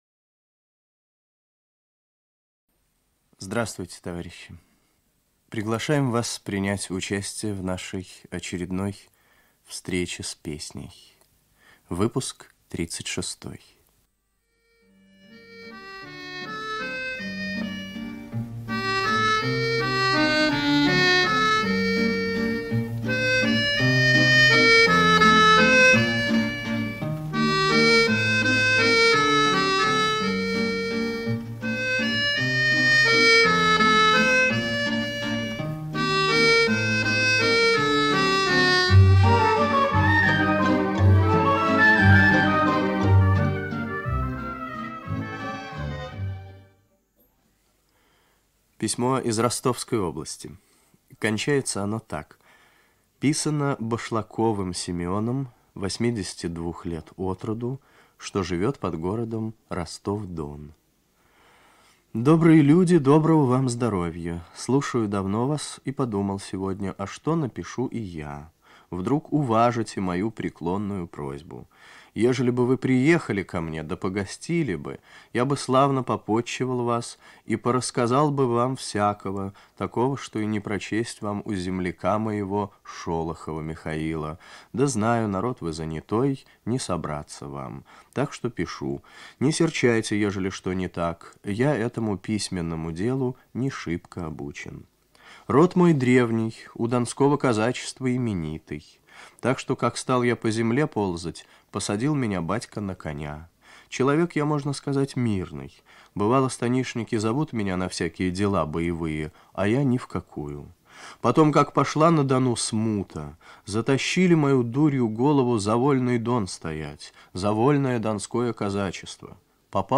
Русская народная песня.
Фронтовая песня
Старинный русский романс
детский хор 9.